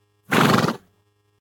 horse_snort2.ogg